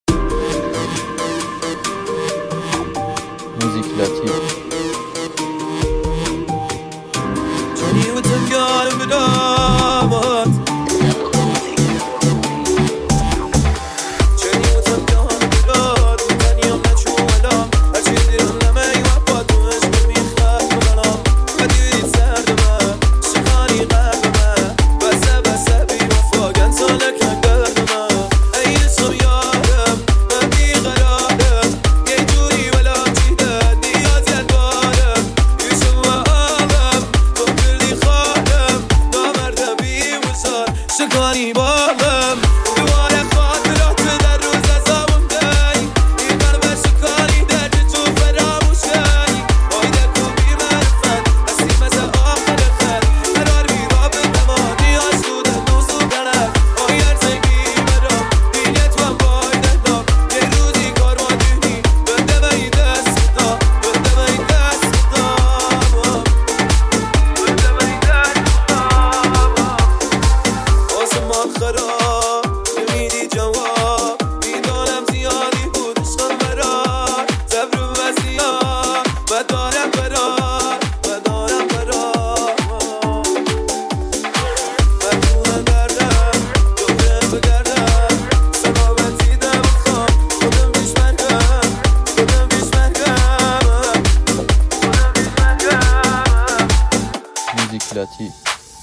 ریمیکس جدید